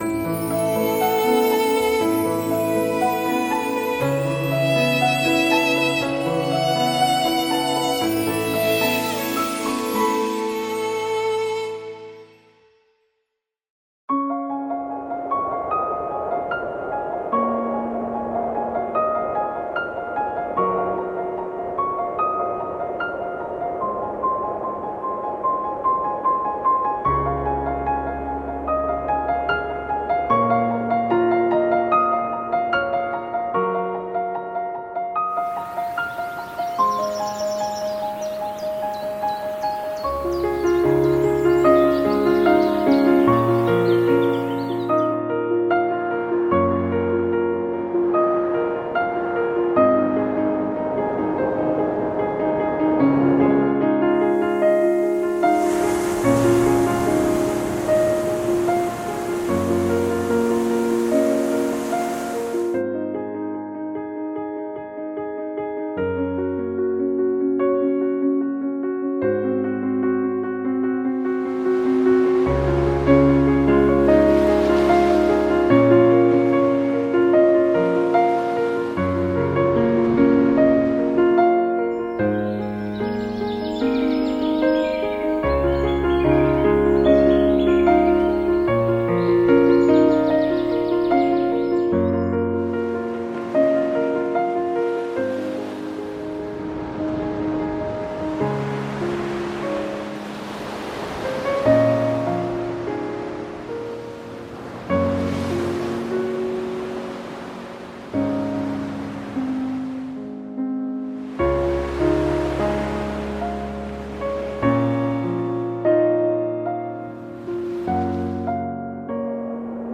Dänische Küsten-Brise-Grüne Felder: Sanfte Brise über entspannenden grünen Feldern